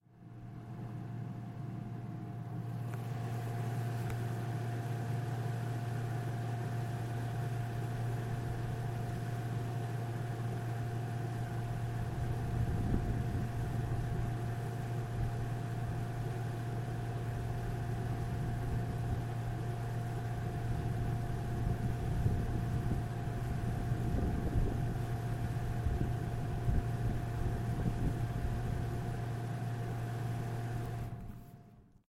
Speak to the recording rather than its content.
Fenland Airfield